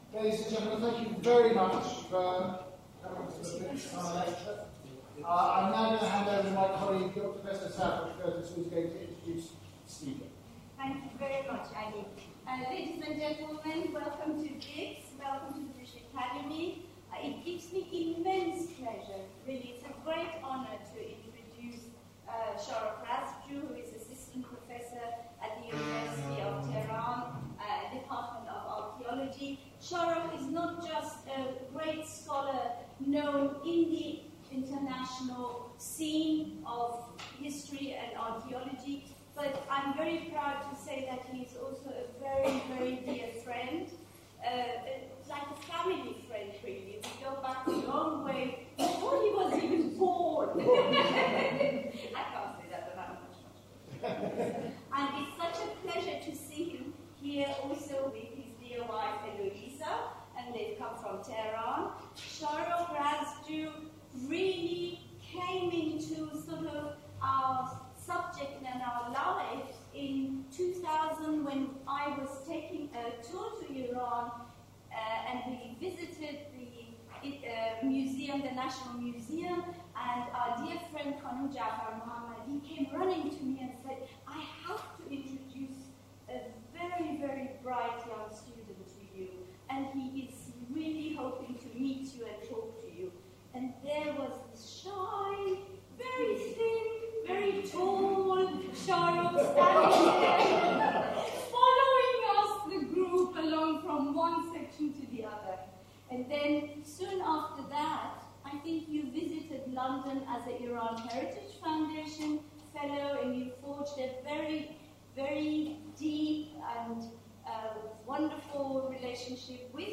9122-BIPS-Lecture.mp3